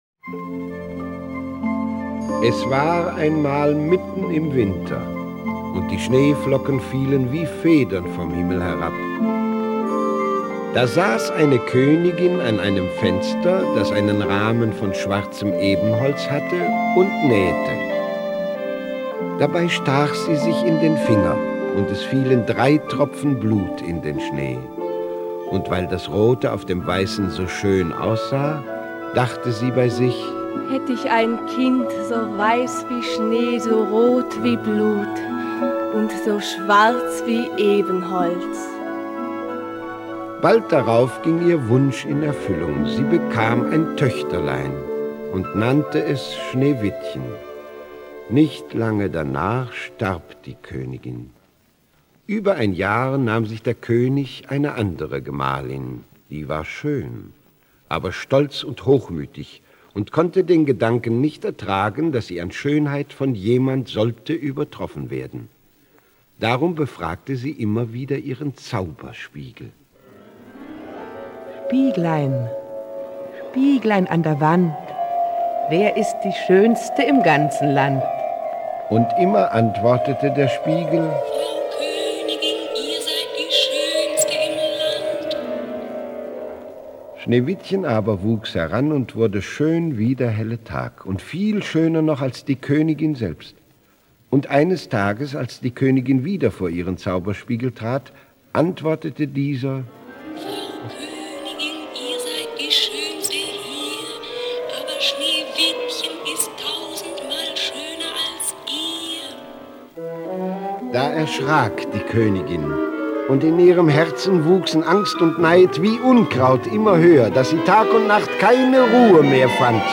Hörspiele mit Eduard Marks, Hans Paetsch, Volker Lechtenbrink u.v.a.